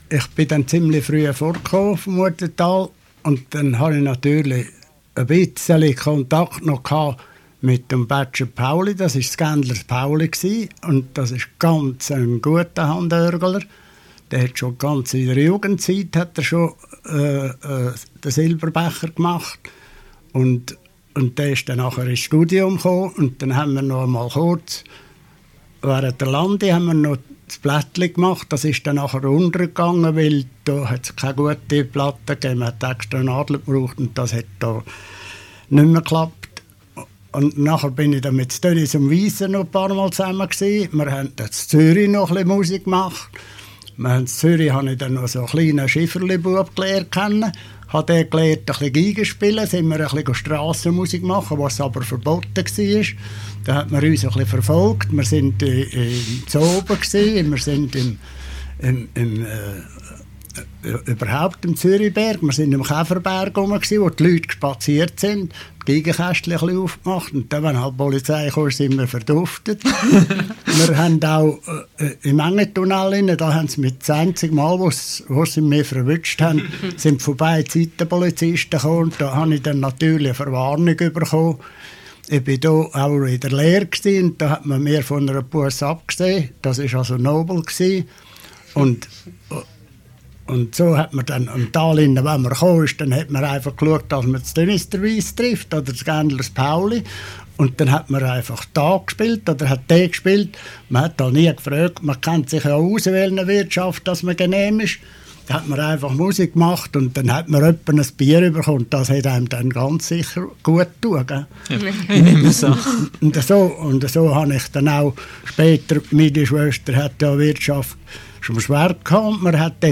Von 1984-1990 brachte das Berner Lokalradio Förderband jeden Sonntag eine Volksmusik-Sendung.
Oft liess ich dabei eine Kassette mitlaufen.
Muothataler Geigers